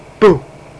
Boo! Sound Effect
boo.mp3